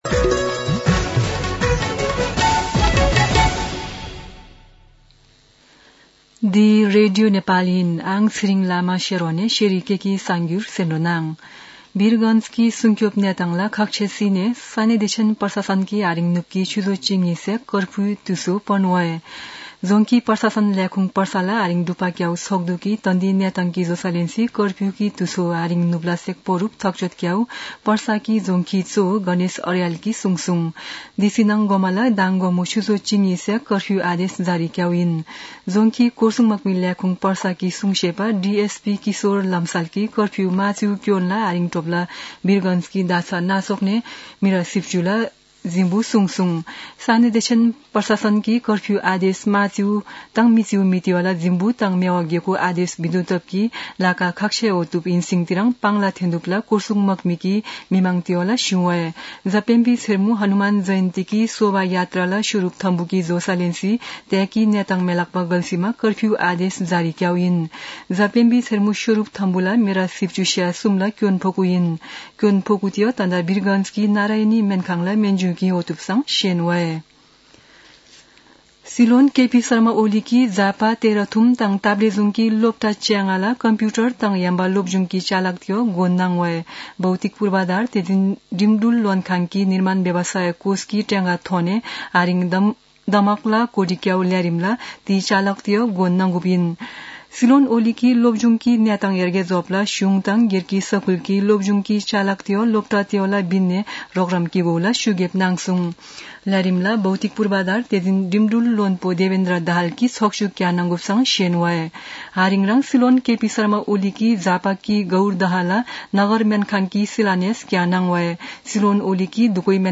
शेर्पा भाषाको समाचार : ३१ चैत , २०८१
Sherpa-News-31.mp3